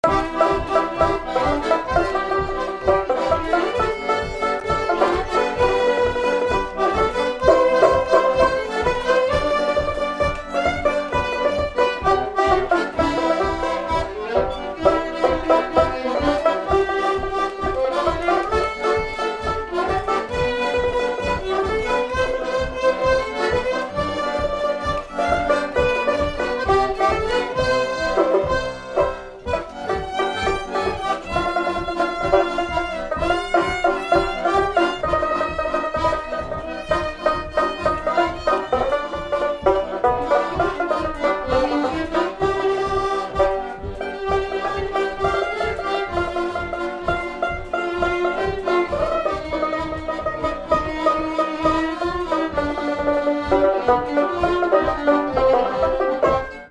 Instrumental
danse : séga
Pièce musicale inédite